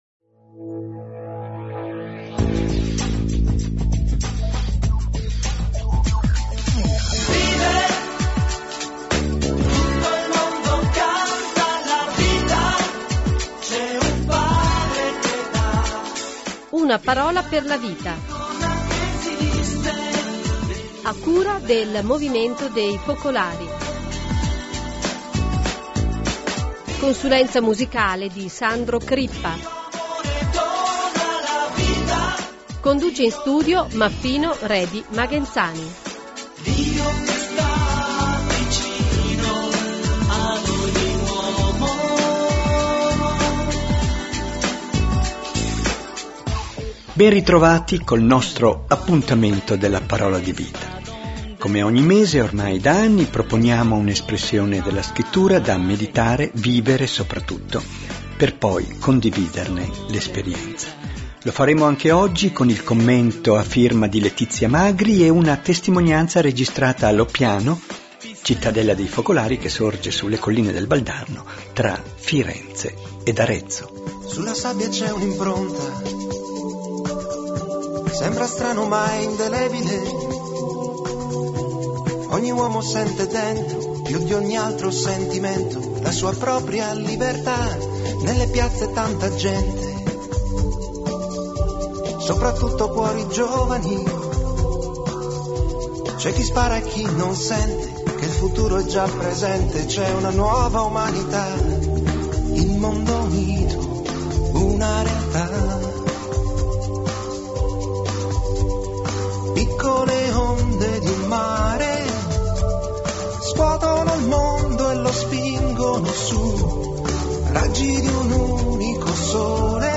Conduce in studio